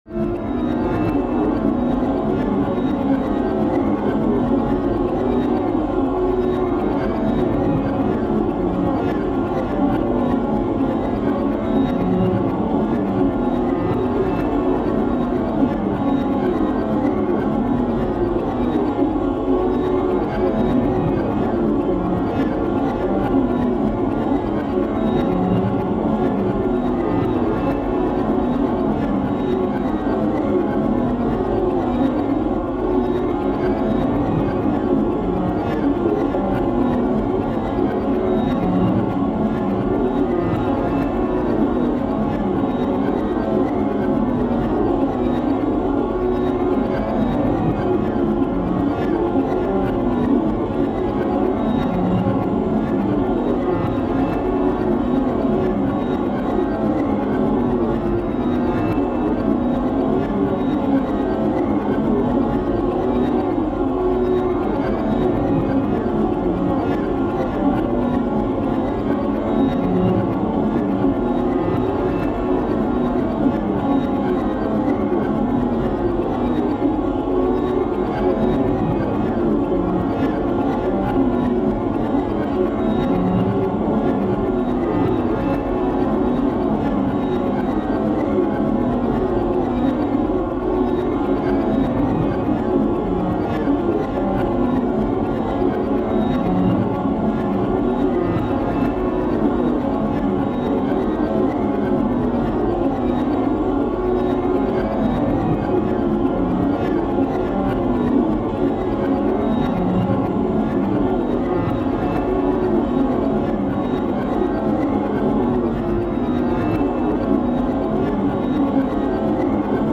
5段階のホラーテクスチャ音源の第一段階。 すでに異様な雰囲気が漂っている。
タグ: ホラー/怖い 変わり種 コメント: 5段階のホラーテクスチャ音源の第一段階。